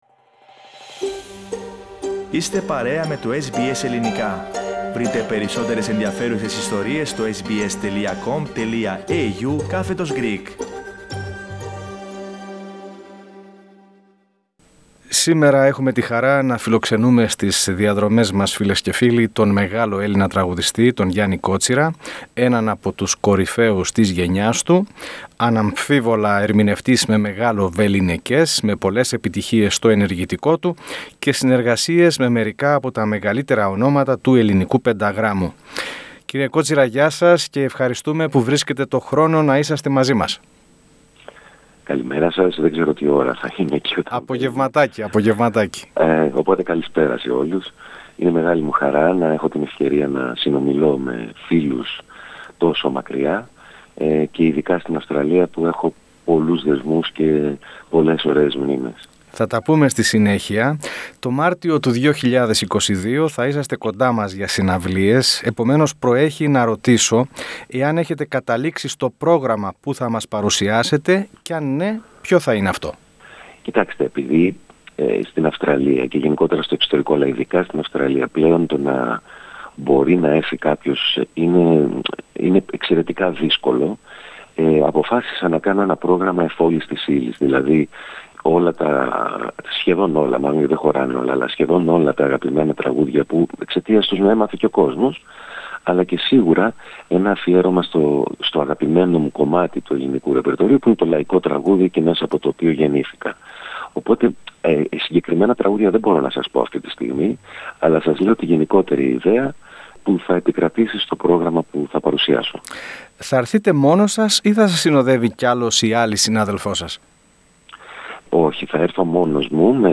Λίγες εβδομάδες πριν τον ερχομό του ο ερμηνευτής παραχώρησε μακρά συνέντευξη στο Ελληνικό Πρόγραμμα της Ραδιοφωνίας SBS (SBS Greek) στην οποία αναφέρθηκε εκτενώς στο ξεκίνημά του και την γενικότερη ενασχόλησή του με το τραγούδι, στις μεγάλες συνεργασίες του καθώς και στο πόσο κοινωνικά συνειδητοποιημένος είναι.